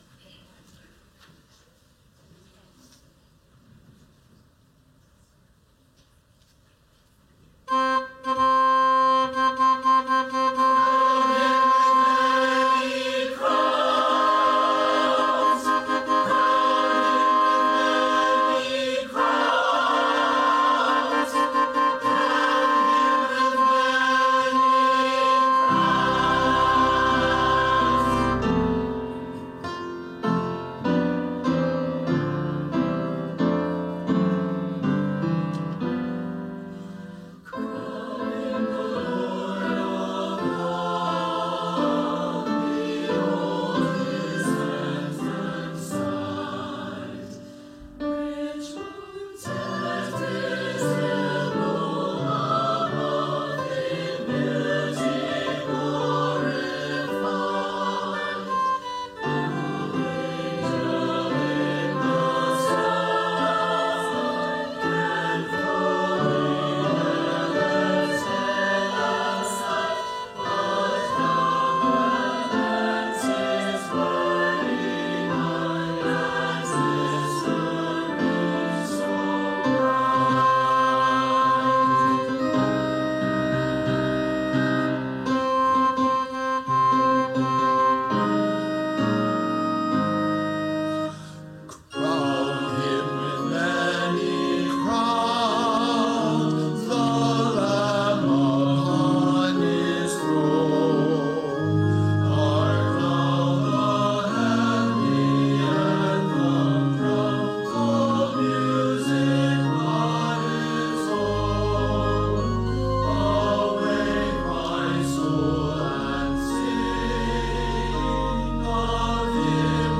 Choir, instrumentalists, and speakers